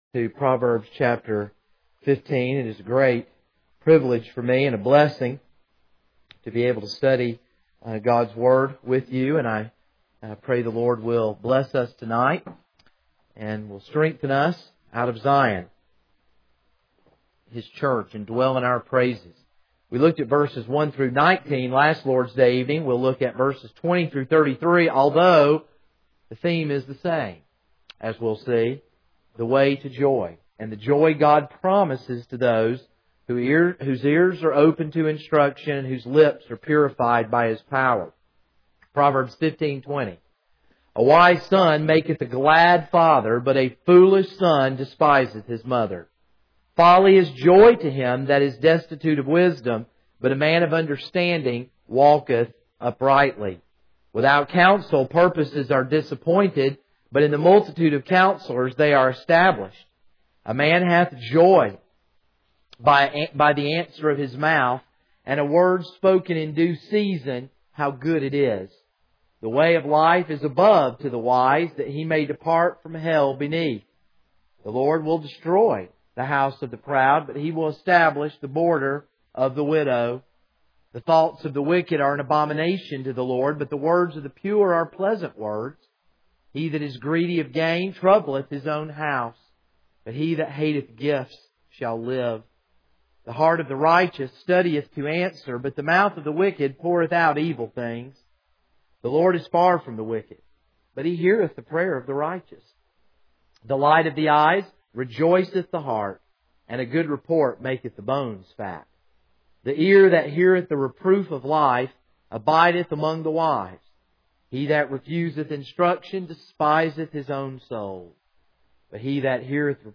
This is a sermon on Proverbs 15:20-33.